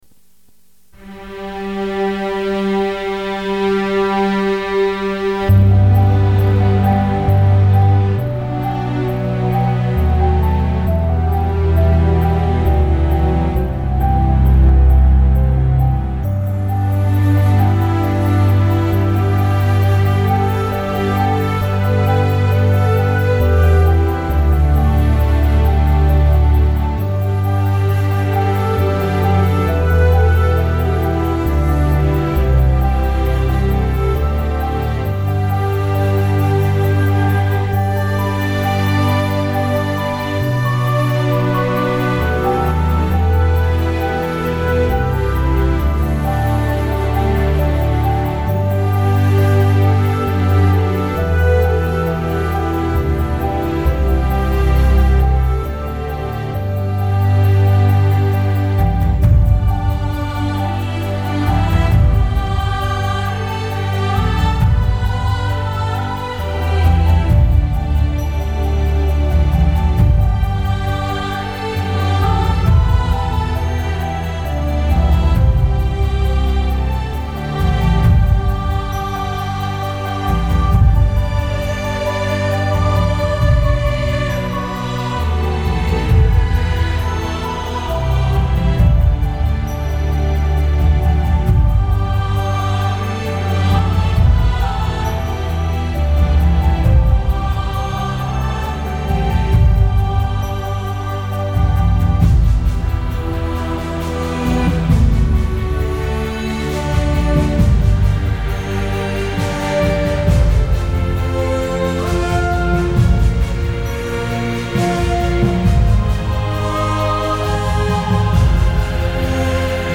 [17/5/2011]阿里郎 震撼的交响乐版本